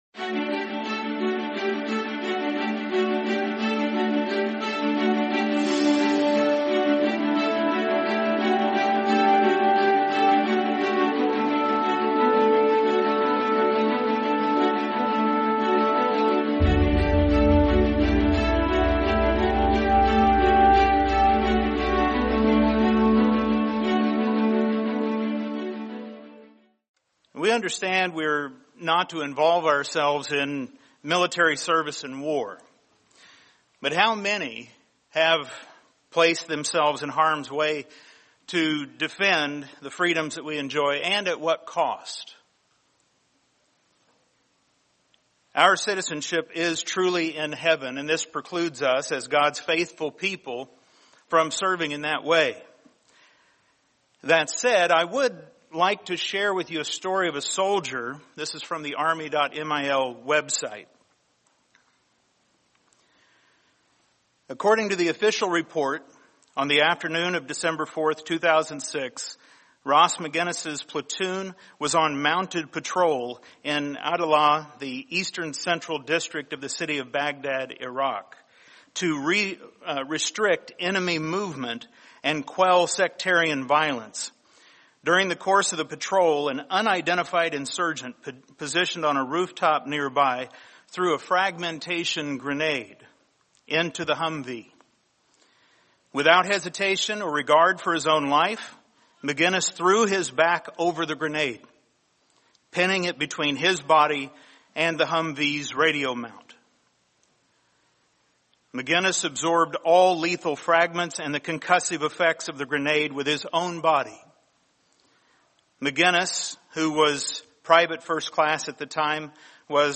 Lay Down Your Life | Sermon | LCG Members